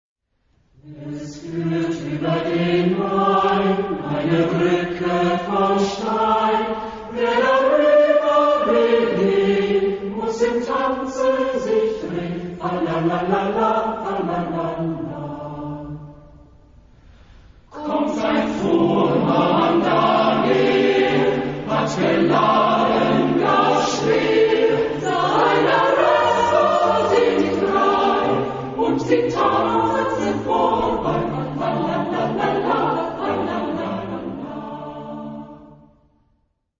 Genre-Style-Form: ballet ; Secular ; Popular
Type of Choir: SATB  (4 mixed voices )
Tonality: D minor